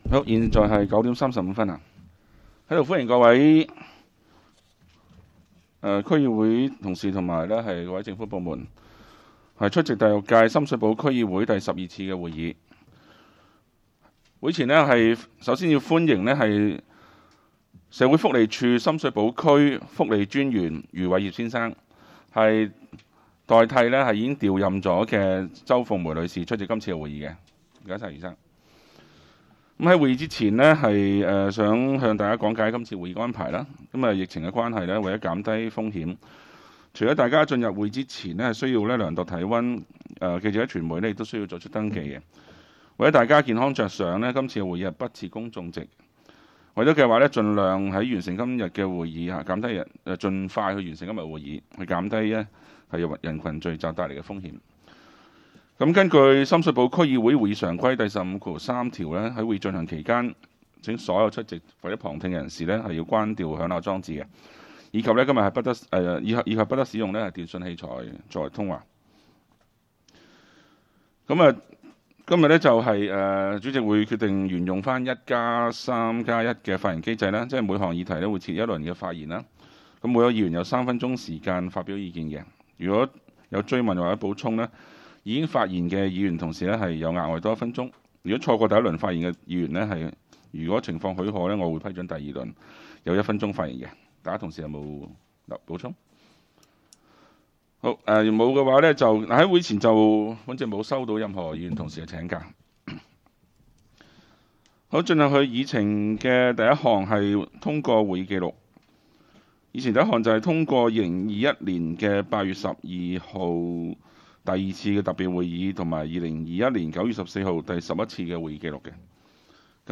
区议会大会的录音记录
深水埗区议会第十二次会议
深水埗区议会会议室